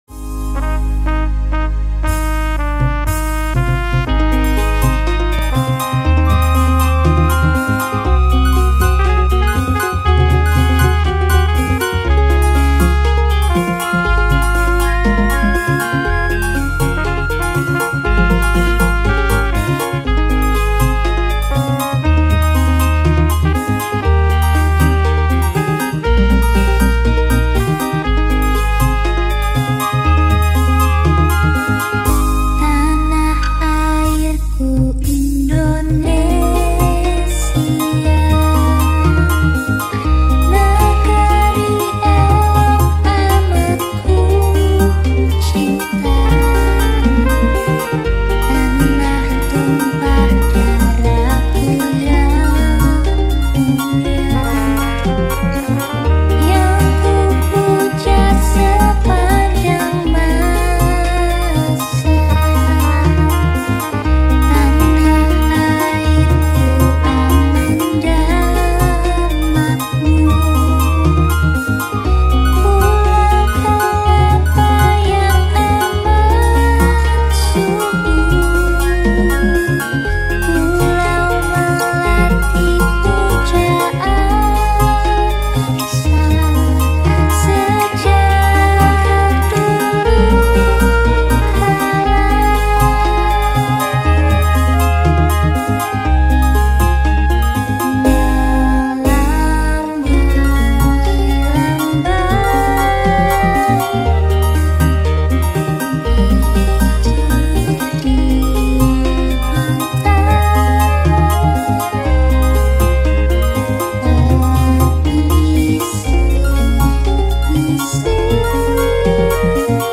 Indonesian Song